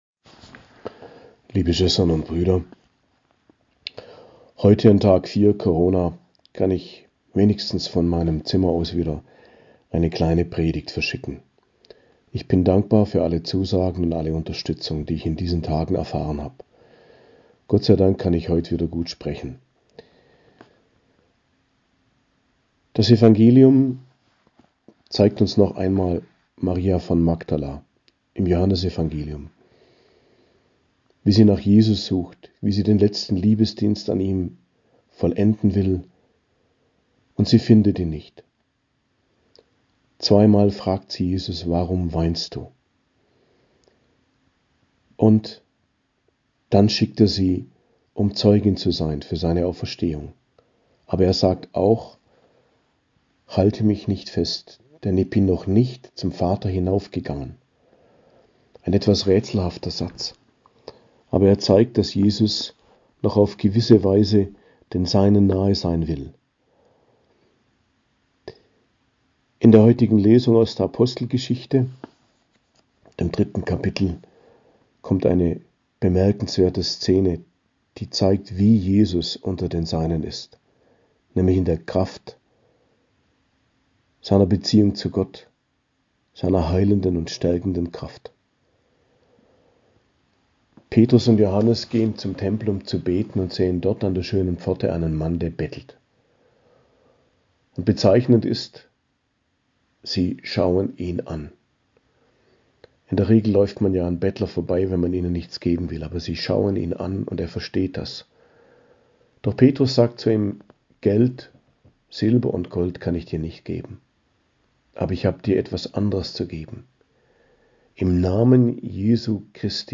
Predigt am Mittwoch der Osteroktav, 20.04.2022